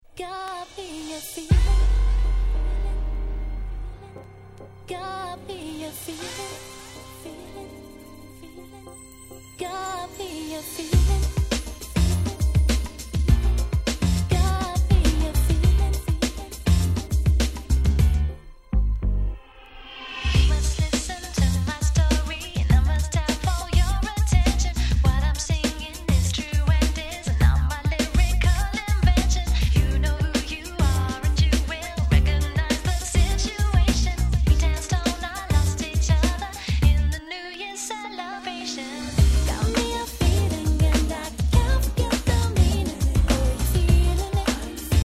UK R&B Classic !!
ゆる〜い原曲があら不思議、これまた疾走感がたまらない踊れる1曲に大変身しちゃってるではありませんか！！